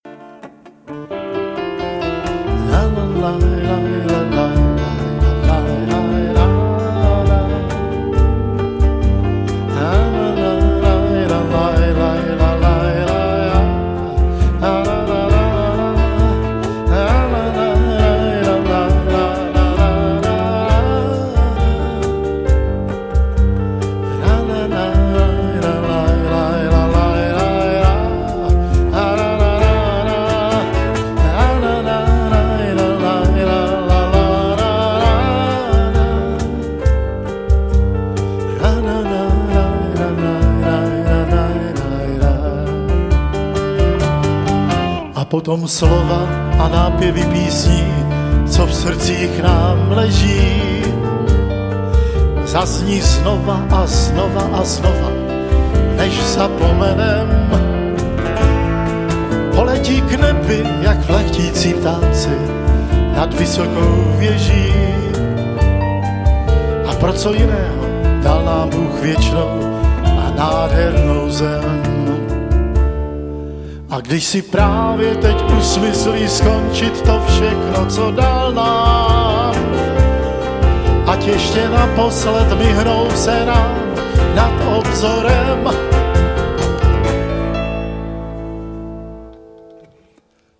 zpěv